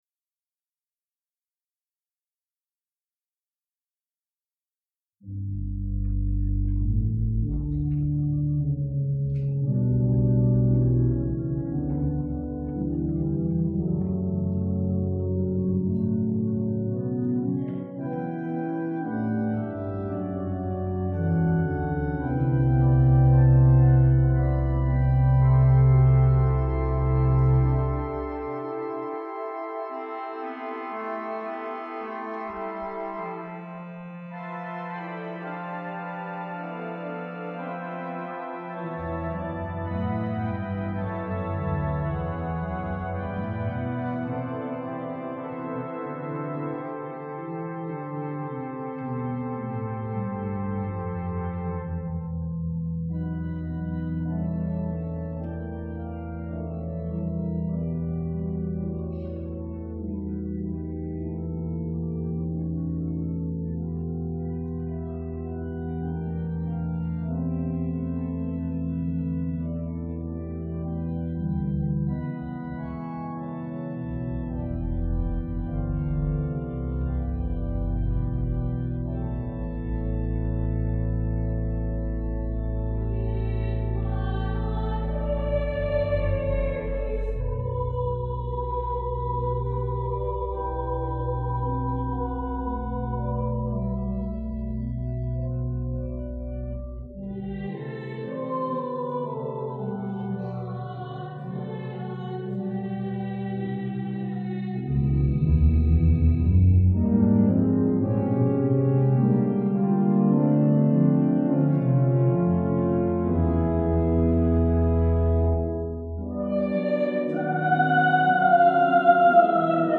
In paradisum Organ and Sopranos - Palm Sunday Mass 2005 - St Ignatius Loyola Parish (84th Street Manhattan)